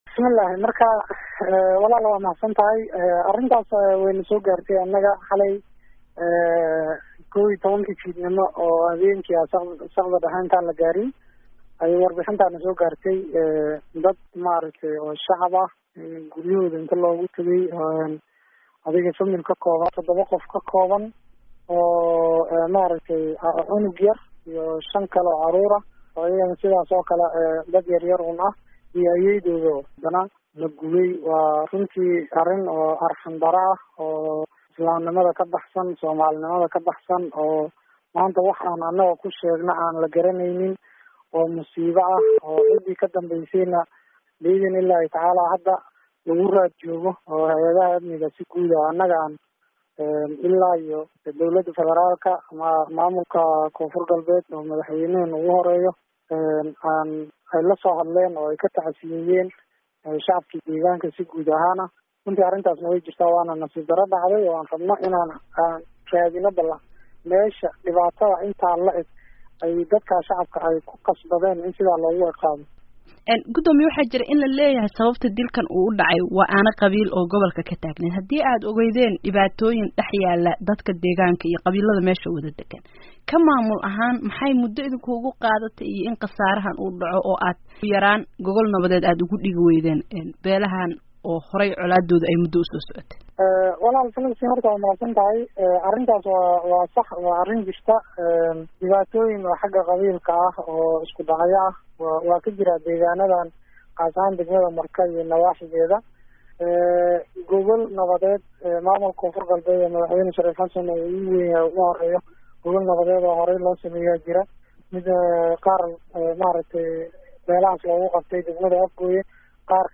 Wareysi: Ibraahim Najax